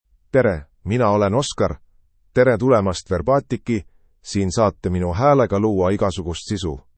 OscarMale Estonian AI voice
Oscar is a male AI voice for Estonian (Estonia).
Voice sample
Listen to Oscar's male Estonian voice.
Male